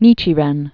(nēchē-rĕn) Originally Zennichimaro. 1222-1282.